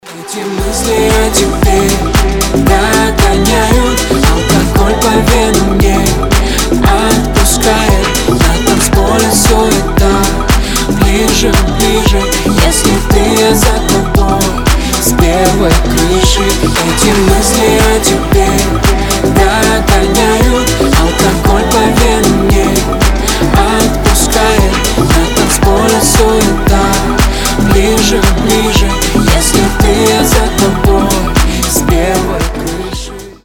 • Качество: 320, Stereo
поп
дуэт